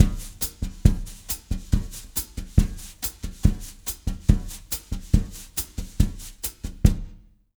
140BOSSA04-R.wav